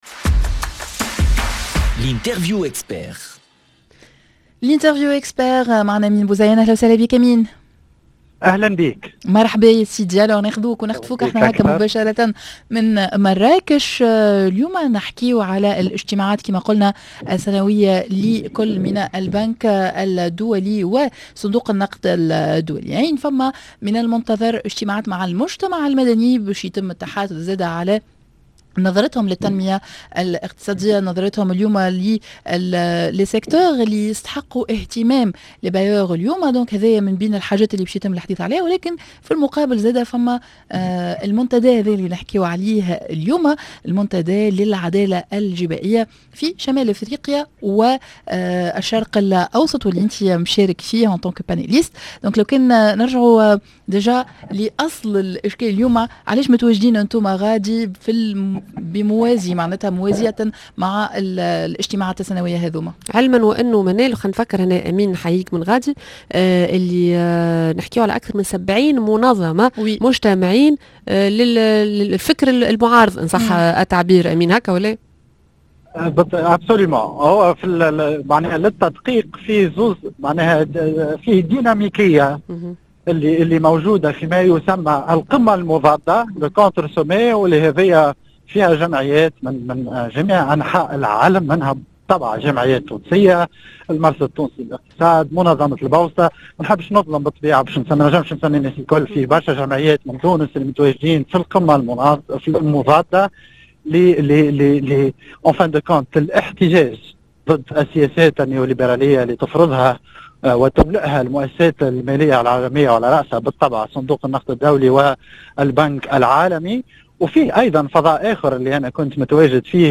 منتدى لعدالة جبائية في شمال افريقيا و الشرق الاوسط- مباشرة من مراكش